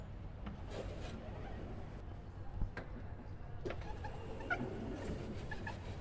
Biblioteca UPF " 脚步声
描述：两个人走下楼梯的声音
标签： 校园UPF UPF-CS12 脚步
声道立体声